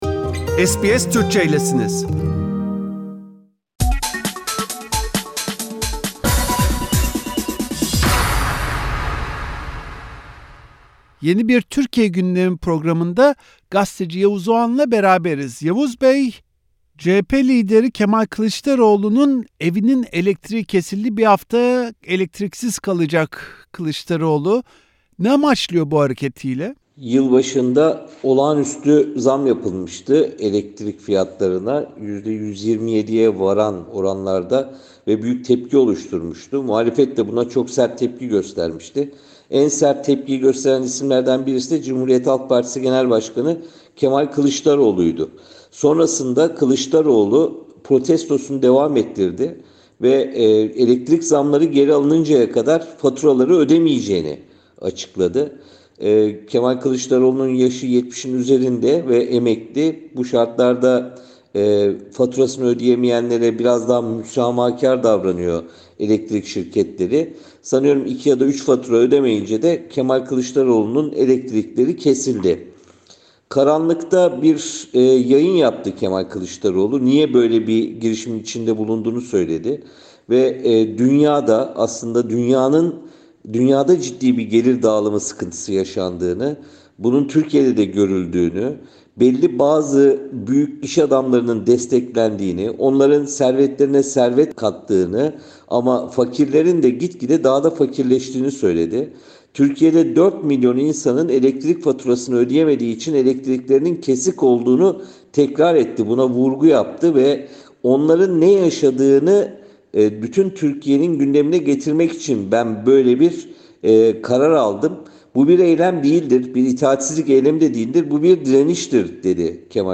Gazeteci Yavuz Oğhan elektriğe gelen zam yüzünden faturasını ödemeyi reddeden CHP Lideri Kılıçdaroğlu’nun siyasi hedefini SBS Türkçe için değerlendirdi.